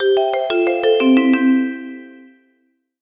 app_storage%2Faudio%2Fnotification-v1.mp3